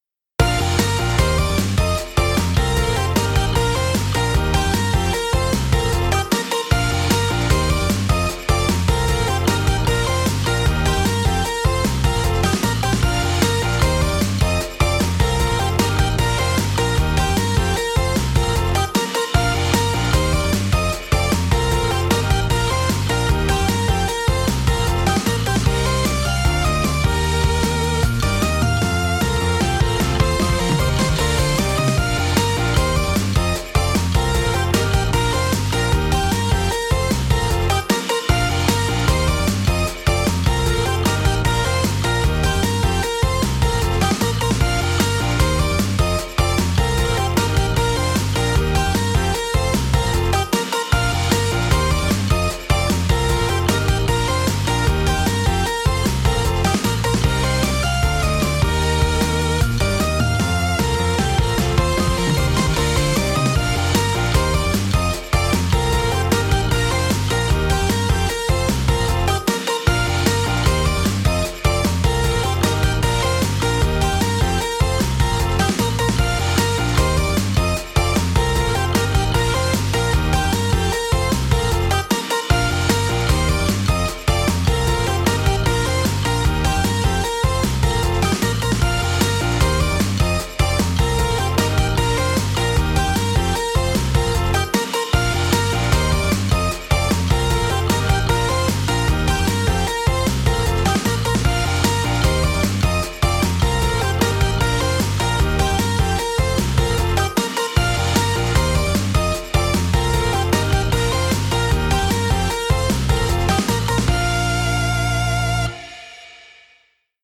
明るい/かわいい/コミカル/疾走感/ポップ
明るくかわいいポップなBGMです。